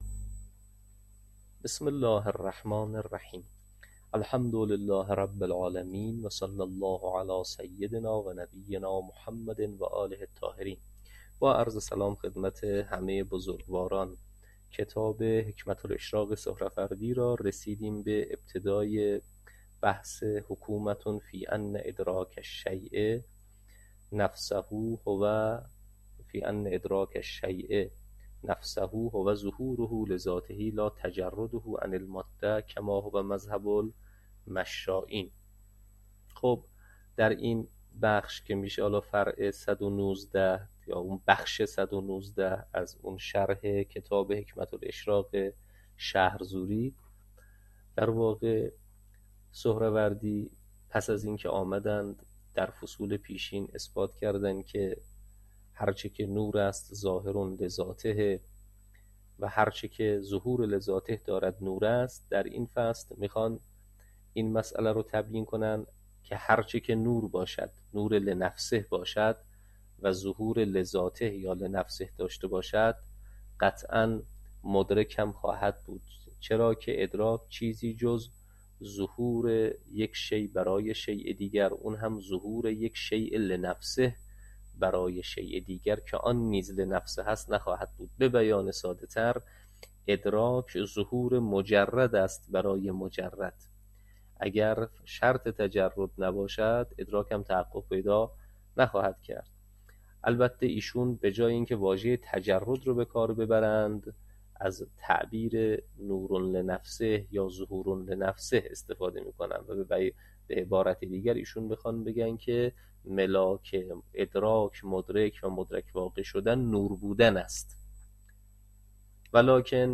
حکمه الاشراق - تدریس